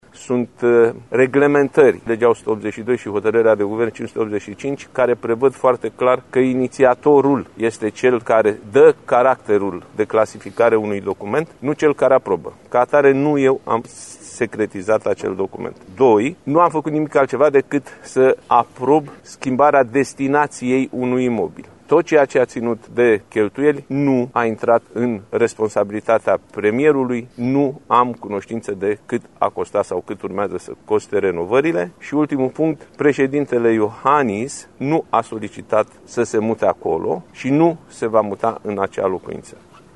Într-o conferință de presă susținută la finalul unei vizite în Cetatea Medievală din Sighișoara, președintele PNL și candidat la alegerile prezidențiale, Nicolae Ciucă a vorbit și despre documentele oficiale privind renovările la vila de pe bulevardul Aviatorilor.